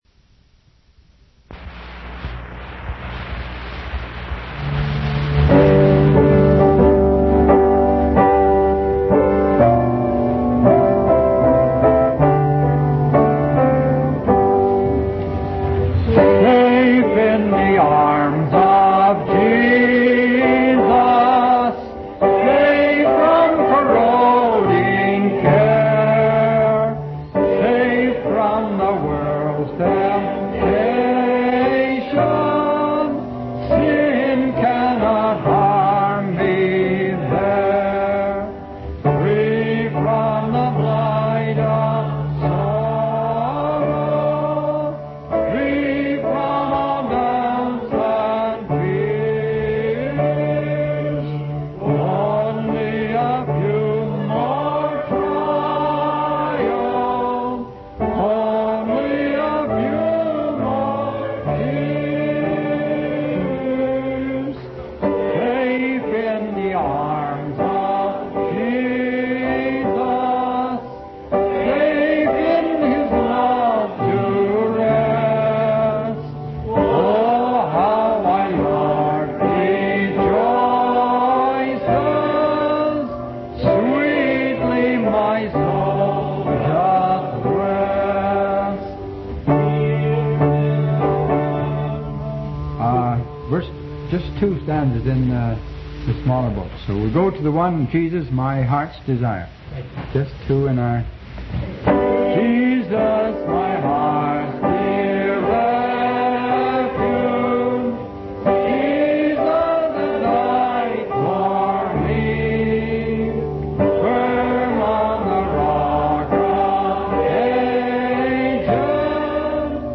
From Type: "Discourse"
Vancouver Canada Convention May 21,1960